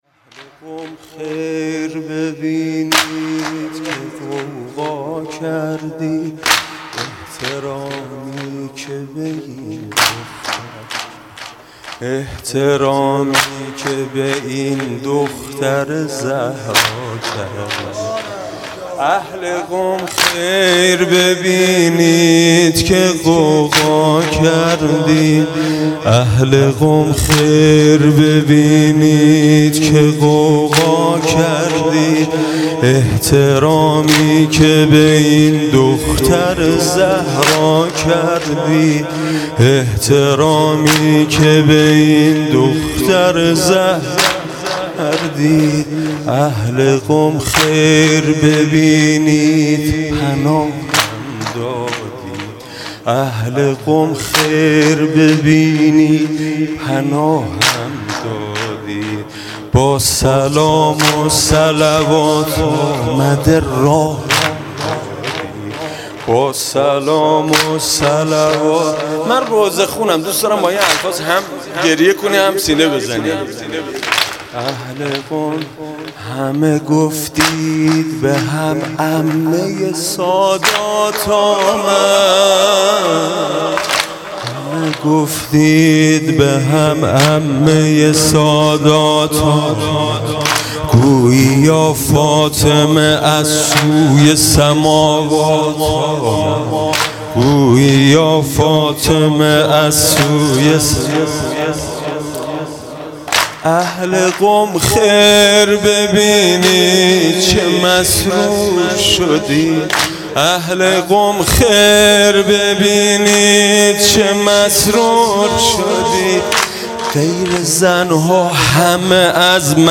هیئت منتظران ظهور شهرستان لنگرود